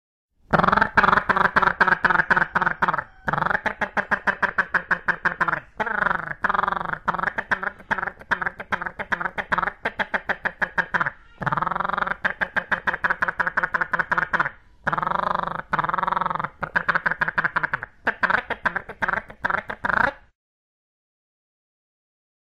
Polinezija megdzioja pauksti.mp3